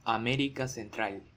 ^ Spanish: América Central [aˈmeɾika senˈtɾal]
Es-pe_-_América_central.ogg.mp3